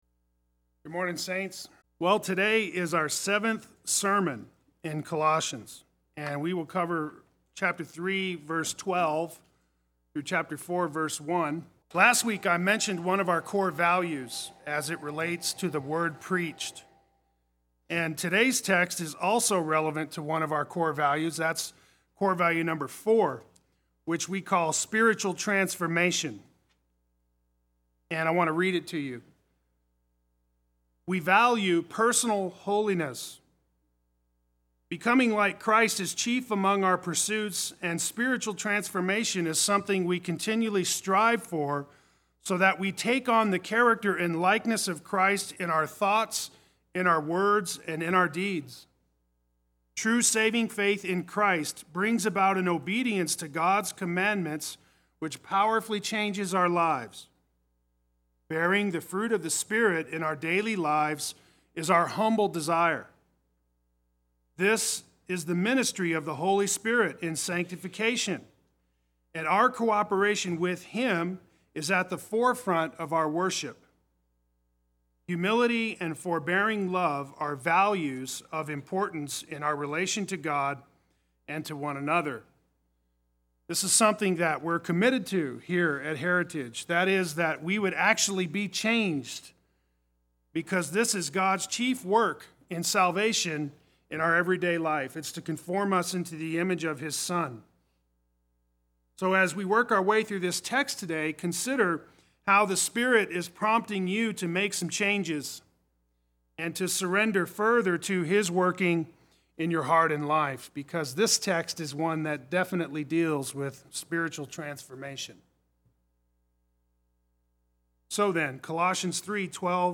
Play Sermon Get HCF Teaching Automatically.
The Life of Virtue Sunday Worship